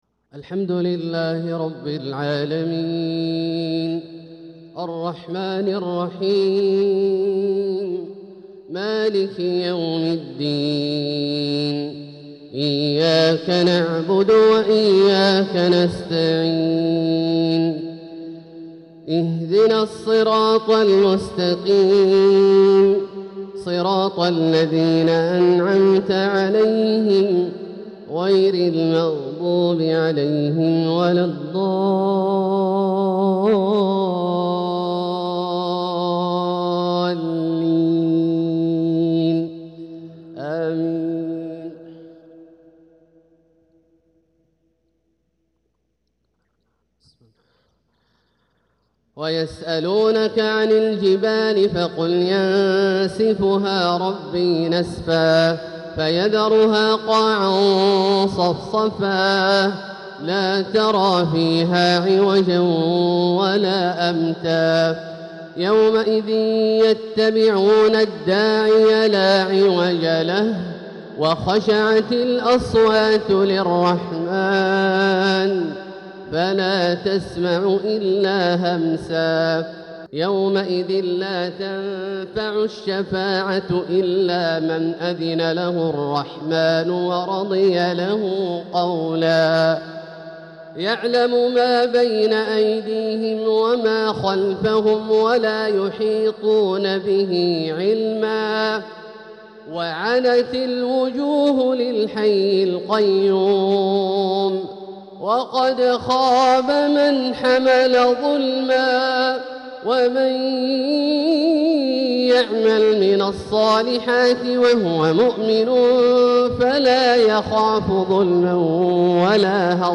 الشيخ عبدالله الجهني بنبراته الشجية الخاشعة يتلو من سوره طه (وعنت الوجوه للحي القيوم) | فجر ٦-٢-١٤٤٧هـ > ١٤٤٧هـ > الفروض - تلاوات عبدالله الجهني